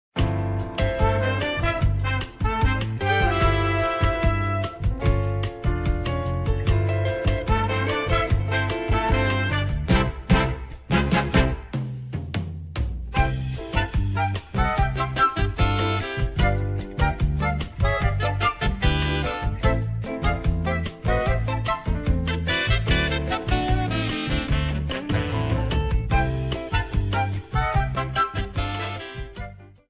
Cropped to 30 seconds with fade-out
Fair use music sample